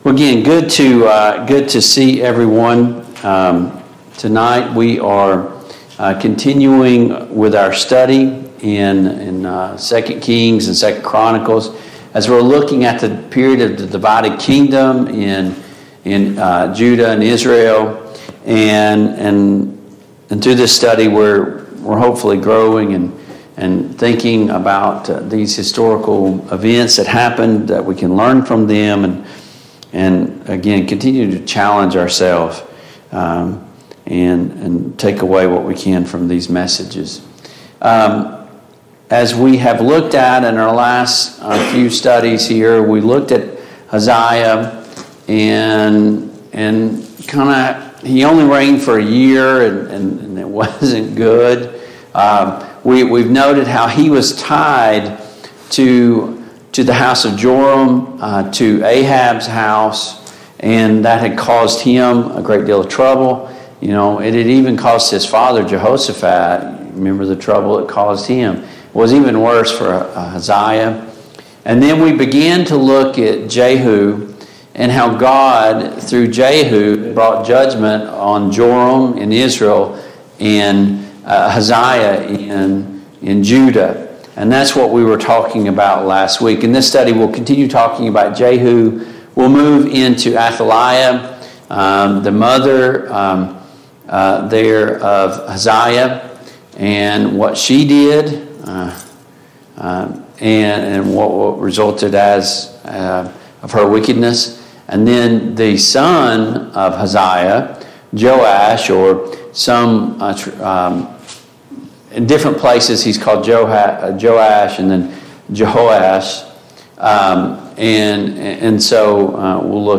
Mid-Week Bible Study Download Files Notes « 4.